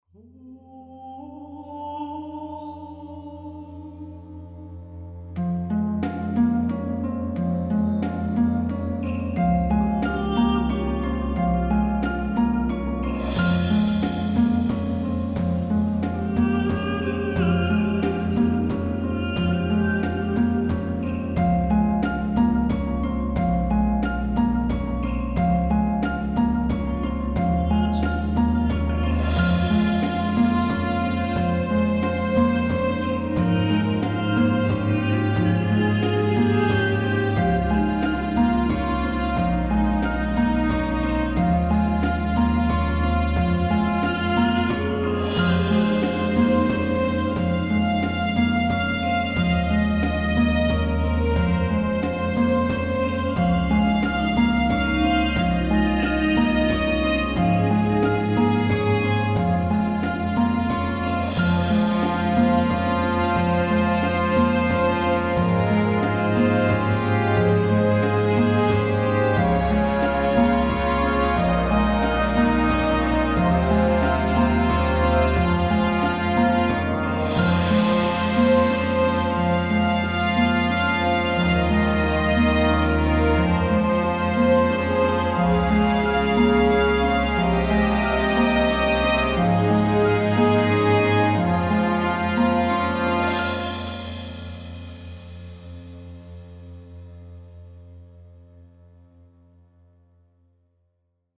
Fantasy RPG theme